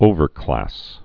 (ōvər-klăs)